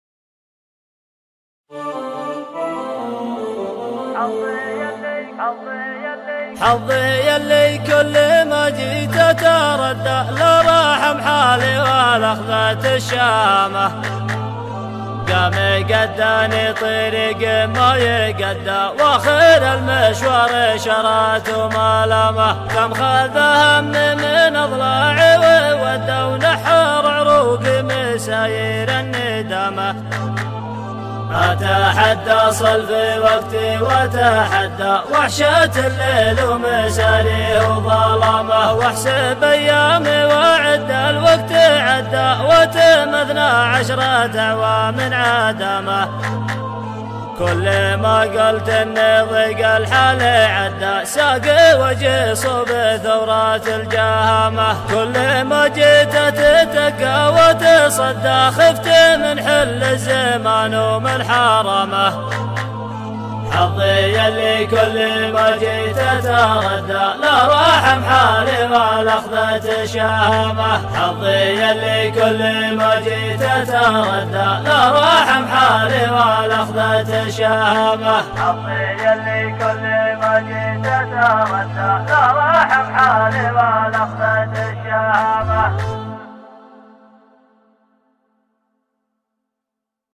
شيلة